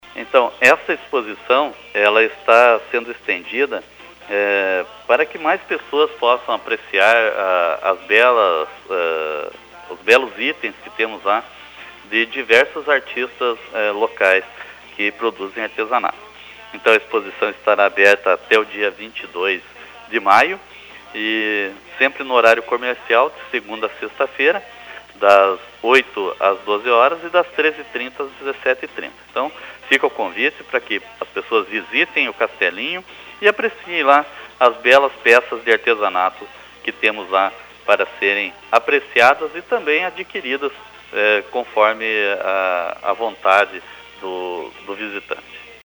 O secretario de Cultura e Turismo de Porto União José Carlos Gonçalves, comenta sobre a exposição e faz o convite para a comunidade prestigiar mais este importante evento cultura e de valorização dos artesãos de Porto União da Vitória.